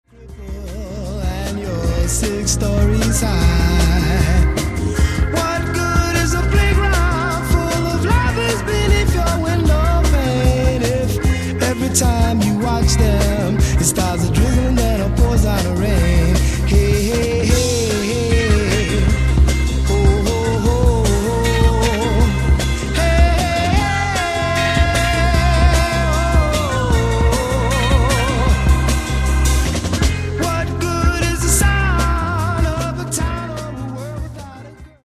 Genre:   Latin Disco Soul